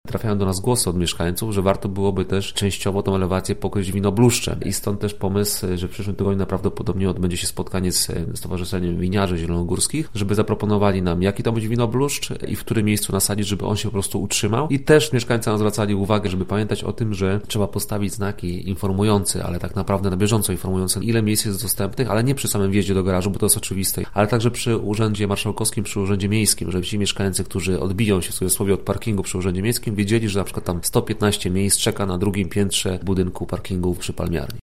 Mieszkańcy miasta mają też dodatkowe propozycje: